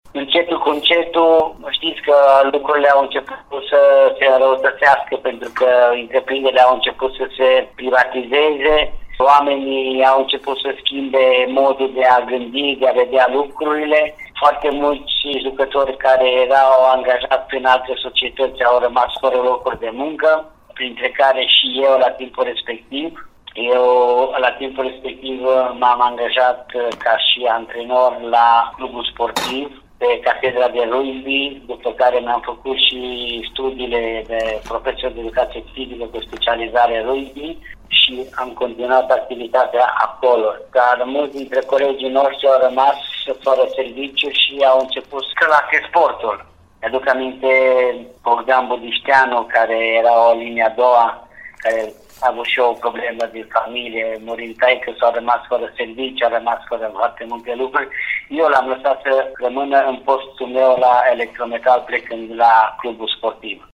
interviului